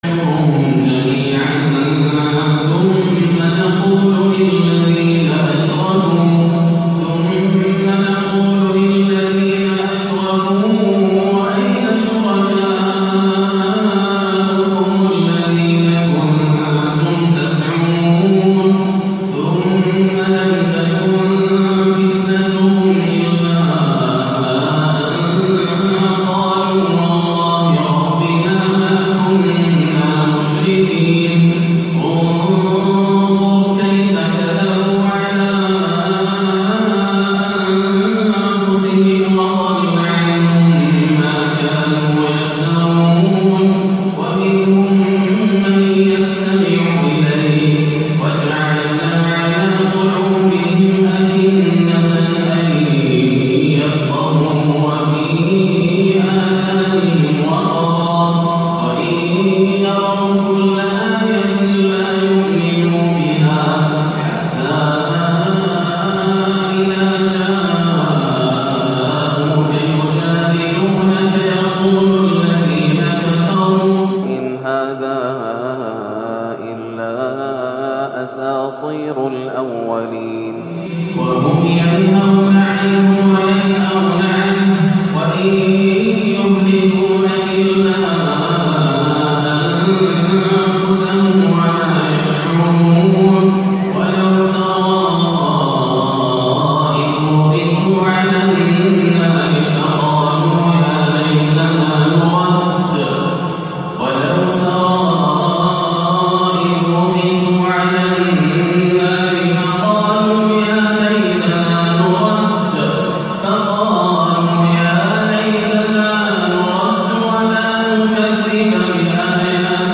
(وَقَالُوا يَا حَسرَتنَا عَلى ما فَرطَنا) وهل تفيد الآماني بعد حلول العذاب - تلاوة باكية من سورة الأنعام - الأحد 5-5-1437هـ > عام 1437 > الفروض - تلاوات ياسر الدوسري